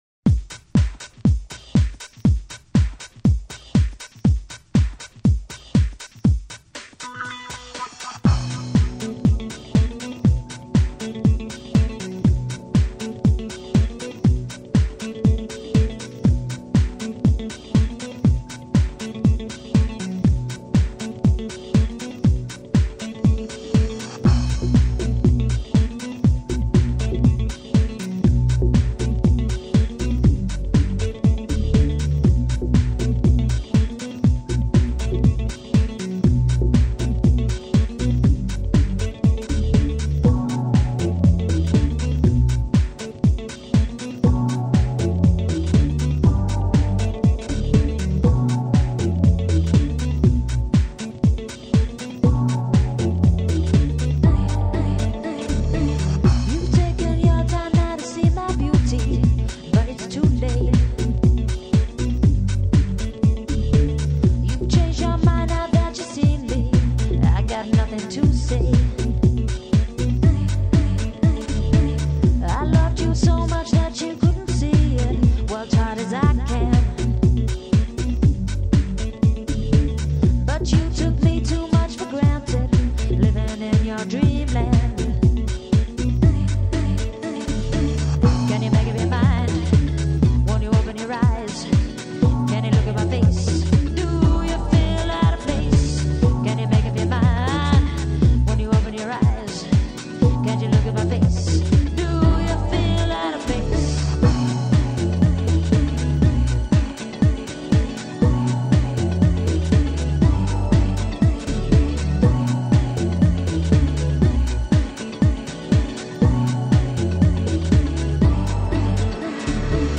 Here are some demo tapes that survived from back in the days...
Dreamland An extended version of the closest thing Sourmash ever got to producing a pop record